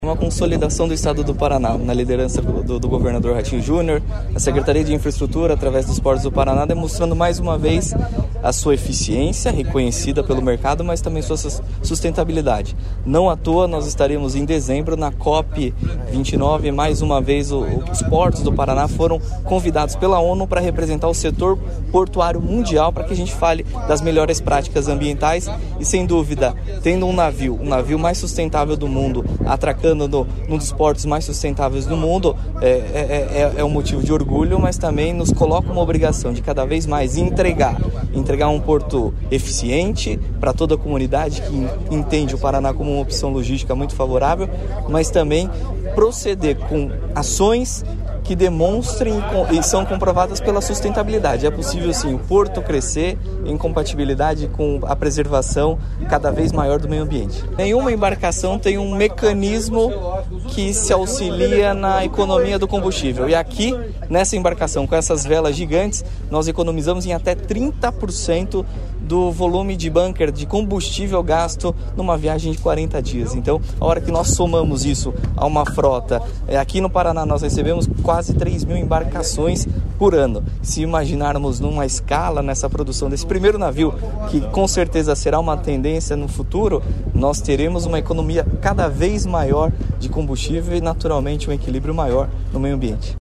Sonora do diretor-presidente da Portos do Paraná, Luiz Fernando Garcia, sobre o primeiro navio sustentável do mundo, que está no Paraná